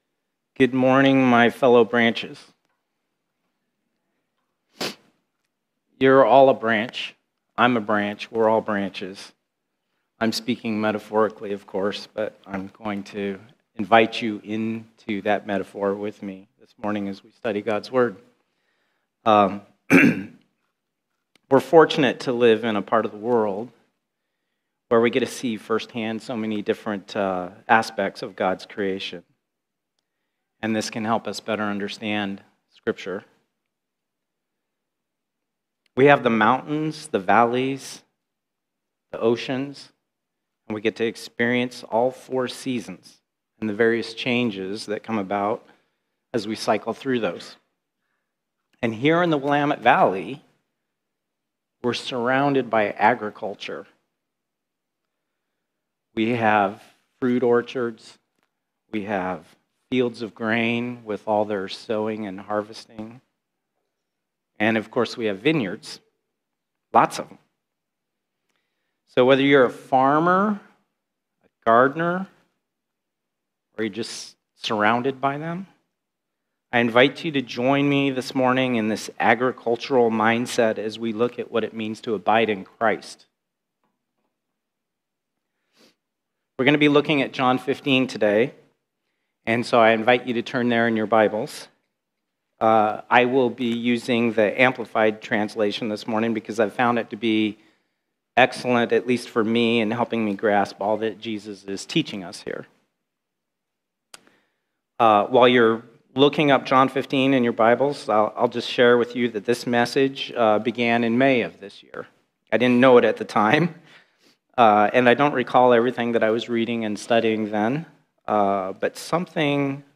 Abiding In Christ Faith Church (Dallas, OR) Sermon podcast